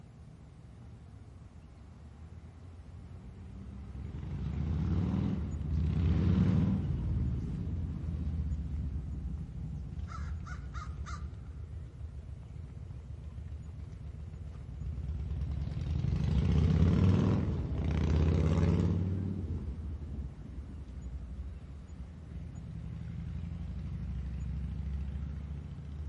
描述：当树上的乌鸦呼唤时，摩托车的进近和离开。